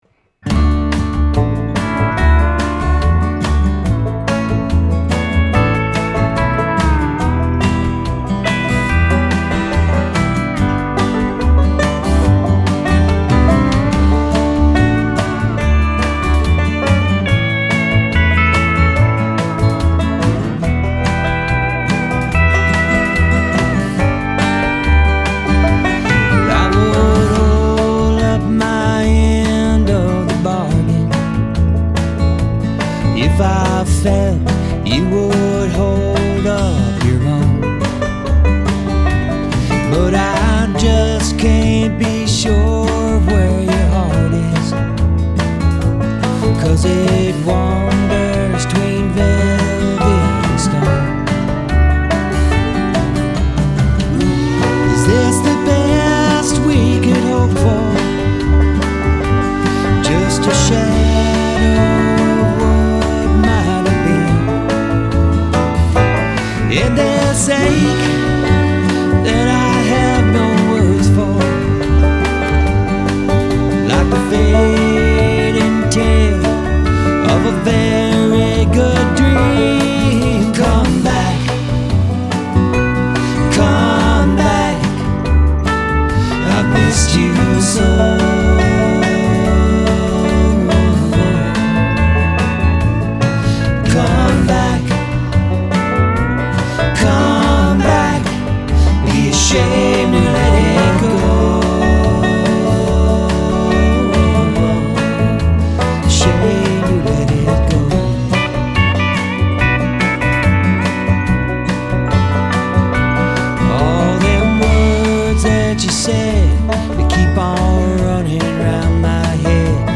Client Recording Session
(my rough mix with pedal steel and banjo)
acoustic guitar